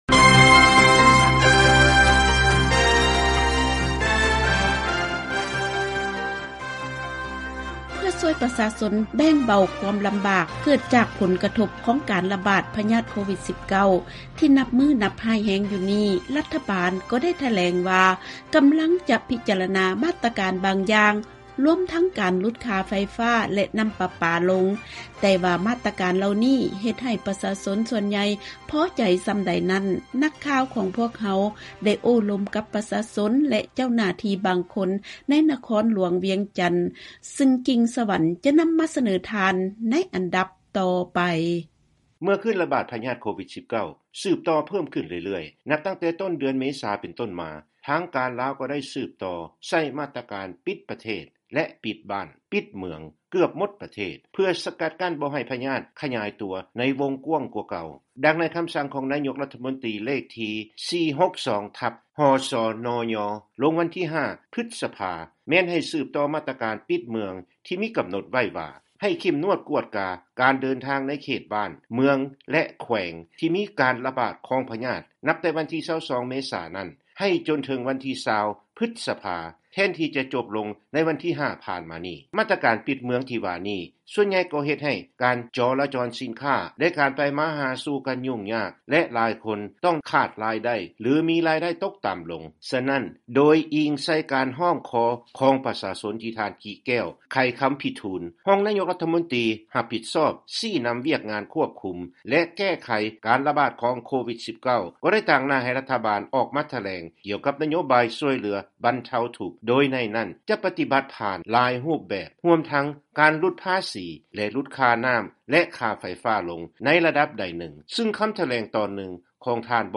ນັກຂ່າວຂອງພວກເຮົາໄດ້ໂອ້ລົມປະຊາຊົນ ແລະເຈົ້າໜ້າທີ່ບາງຄົນໃນນະຄອນ